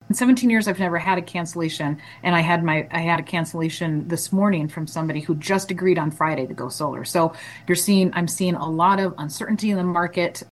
At Monday’s hearing, business owners in the solar industry described how federal tax credit changes have rattled the market.